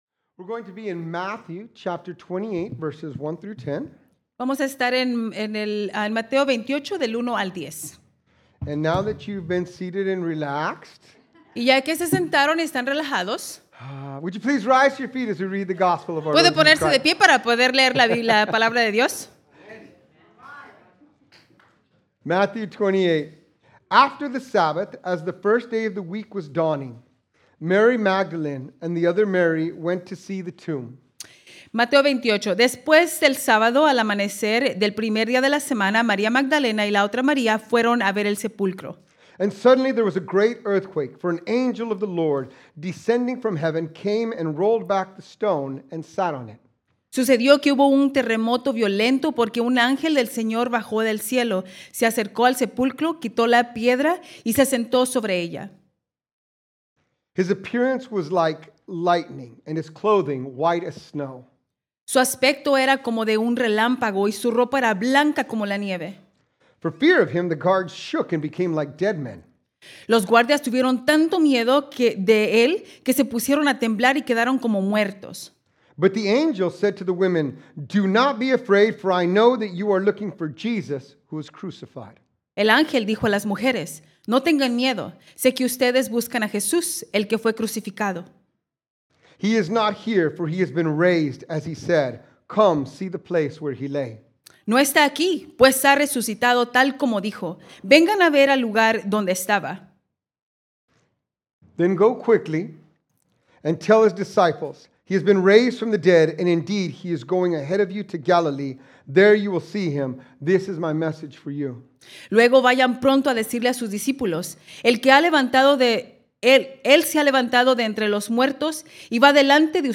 Sermons | Mercy Springs Church of the Nazarene